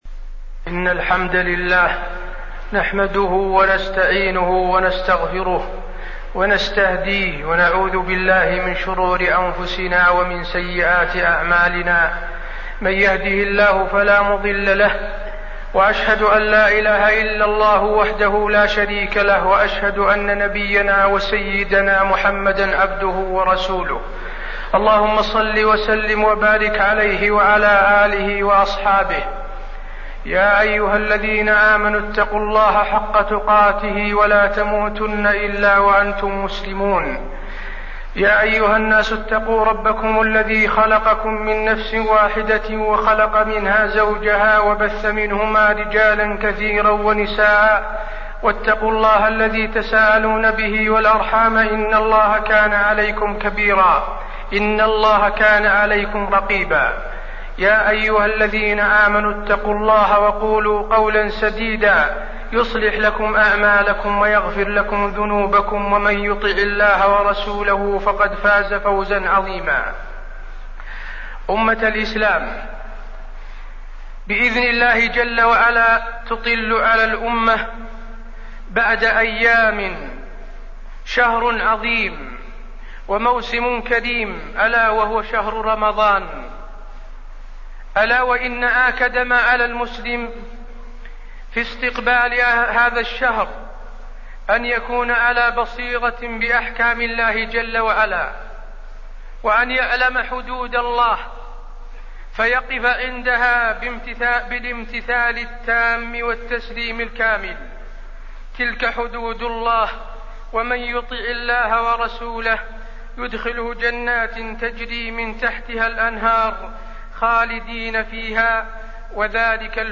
تاريخ النشر ٢٣ شعبان ١٤٣٠ هـ المكان: المسجد النبوي الشيخ: فضيلة الشيخ د. حسين بن عبدالعزيز آل الشيخ فضيلة الشيخ د. حسين بن عبدالعزيز آل الشيخ أحكام الصيام